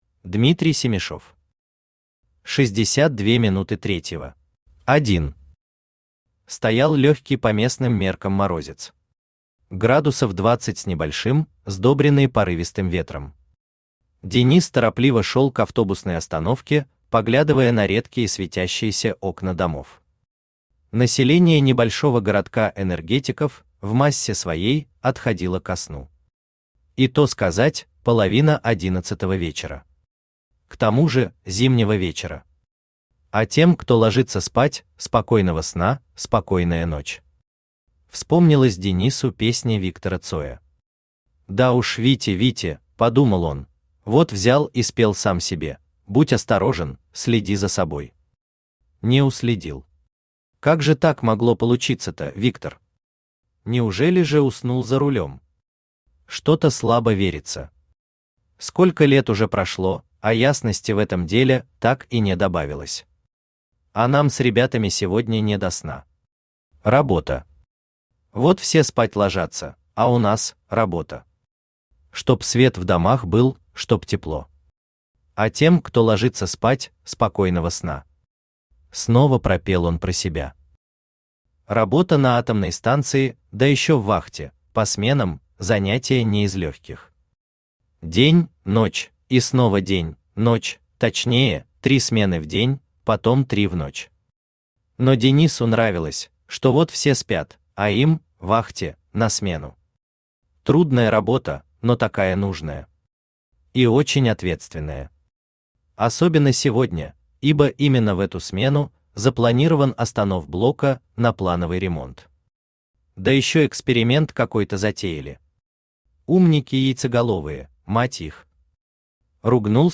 Аудиокнига 62 минуты третьего | Библиотека аудиокниг
Aудиокнига 62 минуты третьего Автор Дмитрий Петрович Семишев Читает аудиокнигу Авточтец ЛитРес.